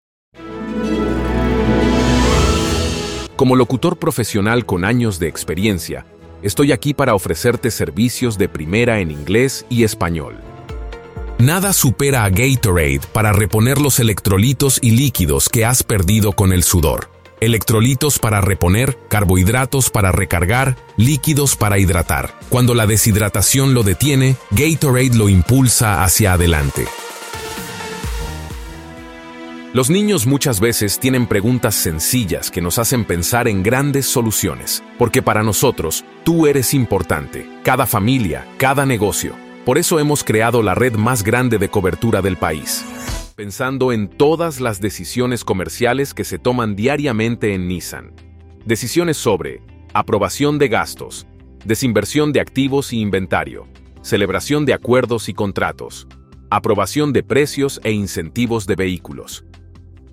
Spanish (Latin American)
Calm
Educated
Authentic